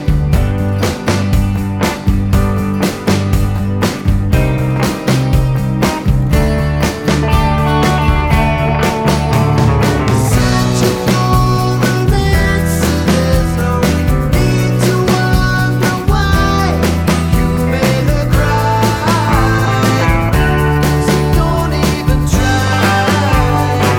no Backing Vocals Rock 2:23 Buy £1.50